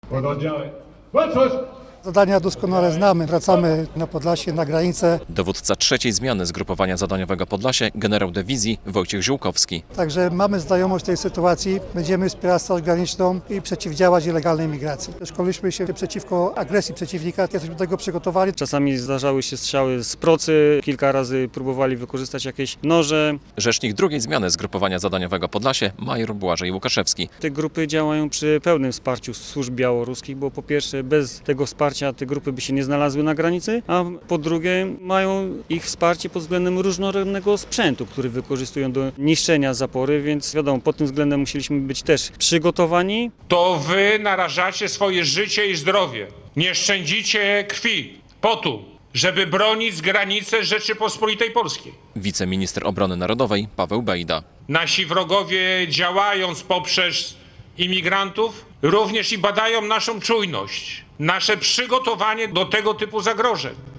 W podbiałostockich Karakulach odbyła się w środę (30.07) uroczystość zakończenia drugiej i rozpoczęcia trzeciej zmiany Zgrupowania Zadaniowego Podlasie.
relacja